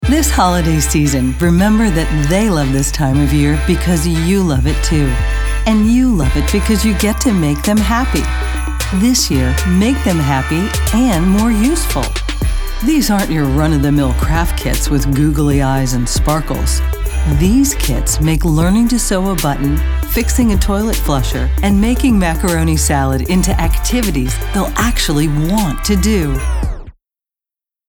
confident, friendly, genuine, mother, perky, soccer mom, upbeat, warm